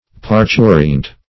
Parturient \Par*tu"ri*ent\, a. [L. parturiens, p. pr. of